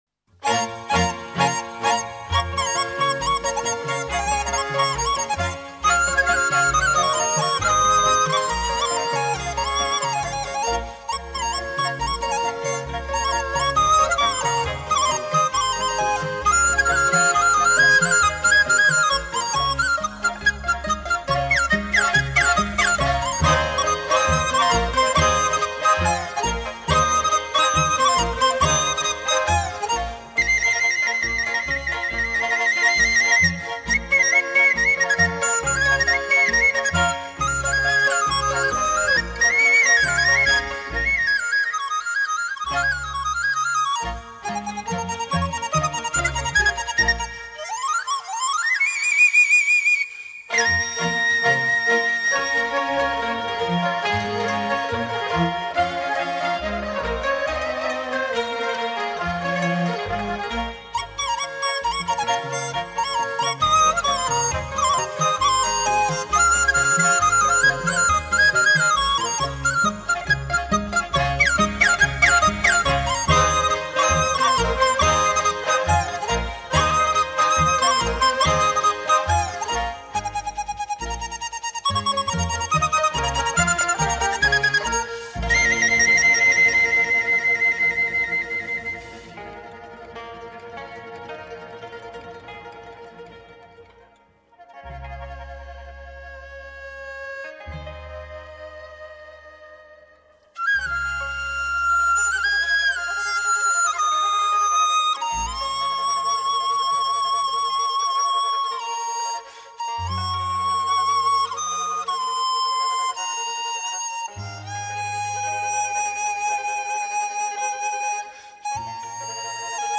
0292-陽明春曉-笛子.mp3